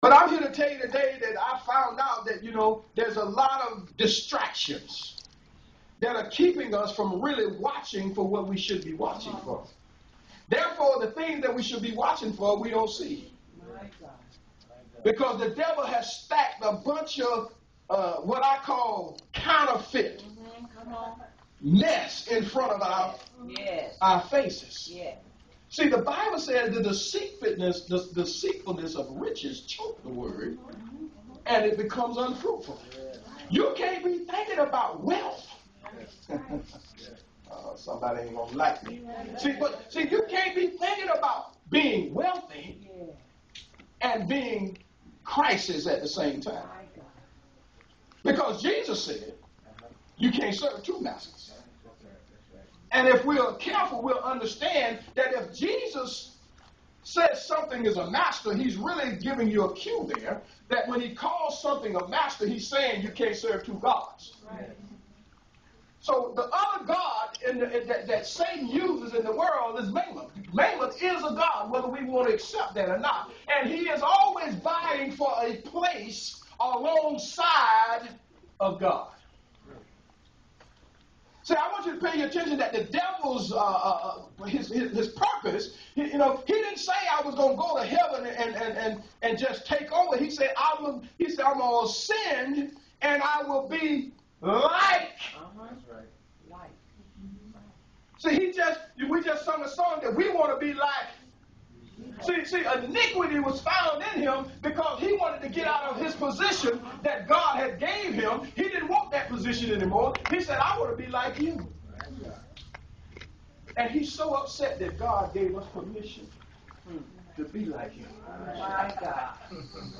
The Reconstruction Sermon!